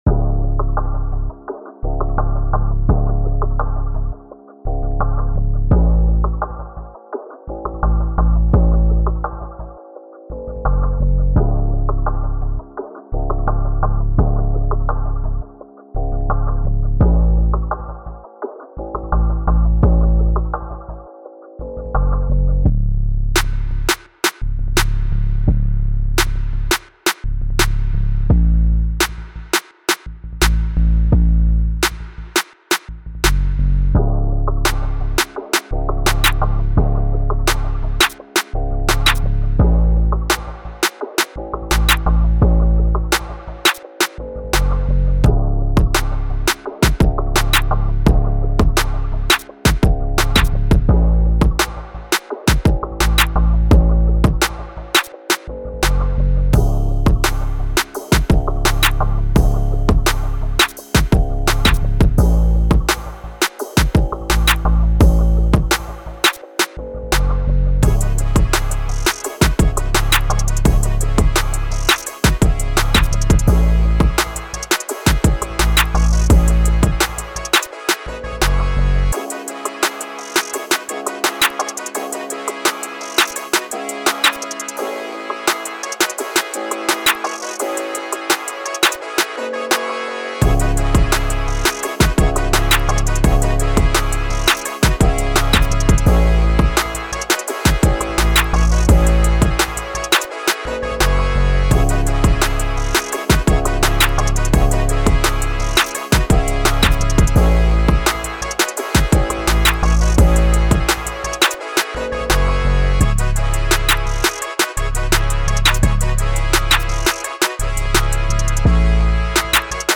05:41 Genre : Trap Size